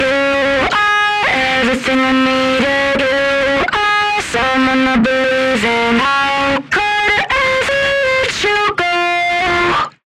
И быстрый жесткий тюн и дисторшен имеются. Пример прикрепил с экстремальными настройками.